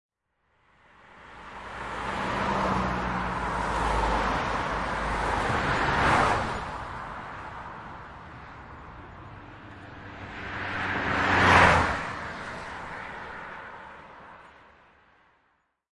交通公路通行2
描述：公路交通记录为Zoom H4N 48KHz 24 bit
Tag: 公路 道路 交通 汽车